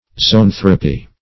Zo*an"thro*py